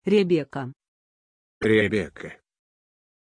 Pronunția numelui Rebeka
pronunciation-rebeka-ru.mp3